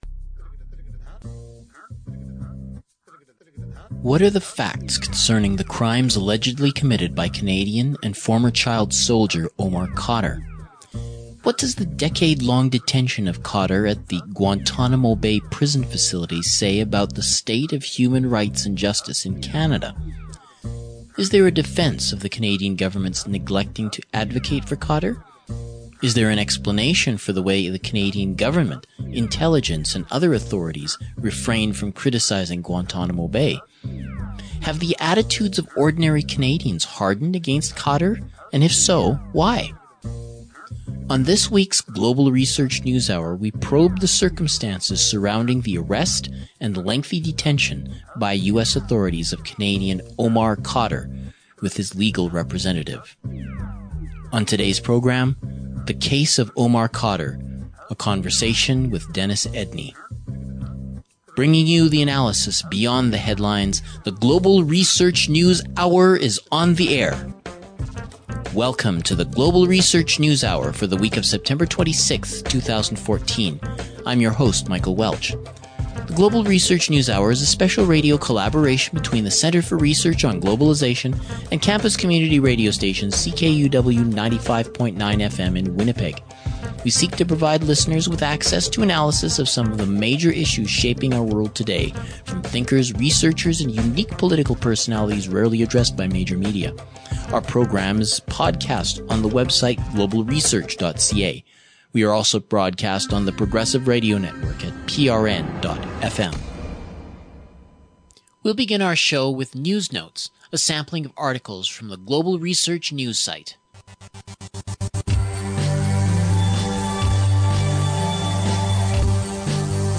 The Case of Omar Khadr: A Conversation